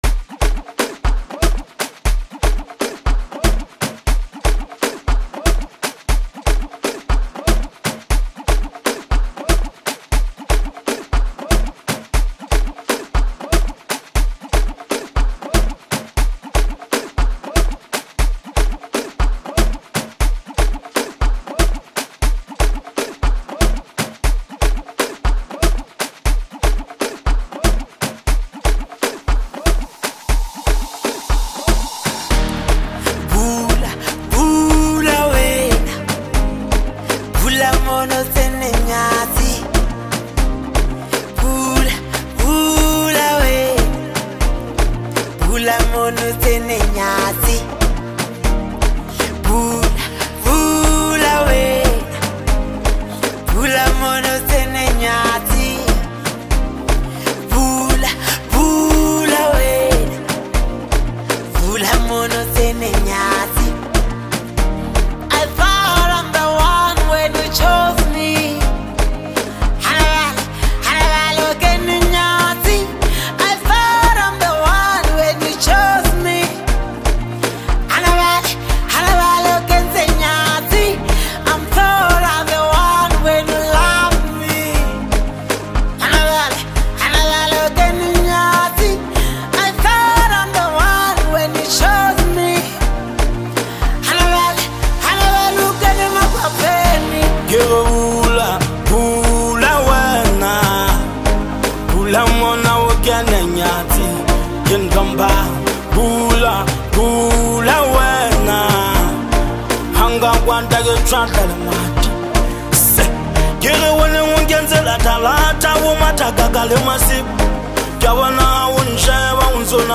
DANCE Apr 07, 2026